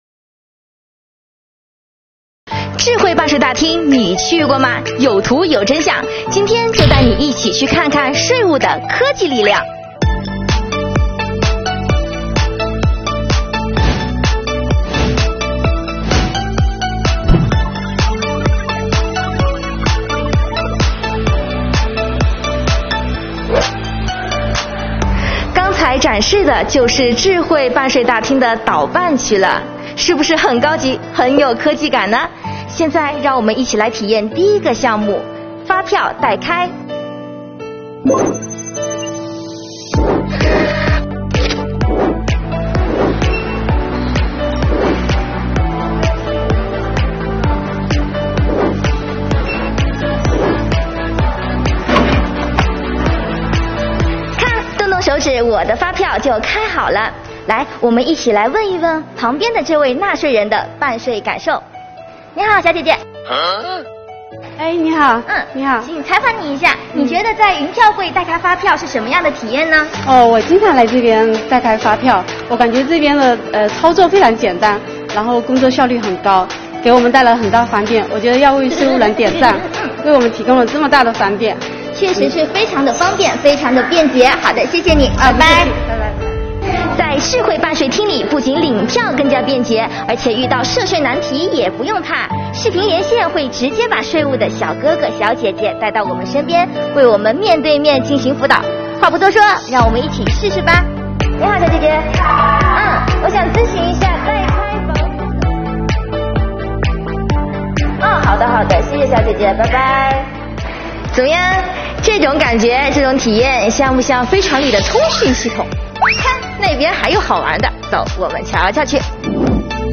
标题: Vlog丨跟随体验官小姐姐去感受税务的科技力量！
听听纳税人的感受